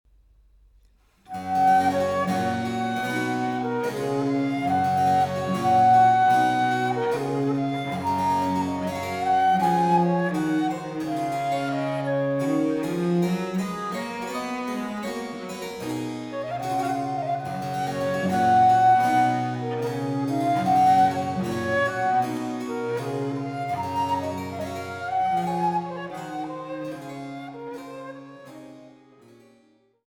Tres vîte